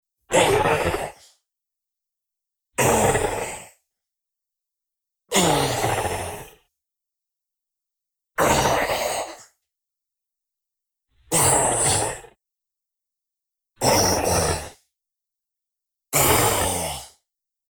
Equilibramos la ganancia de las 4 pistas y así quedaría la ventana de edición de Pro Tools 12:
Y este sería el resultado de la mezcla final: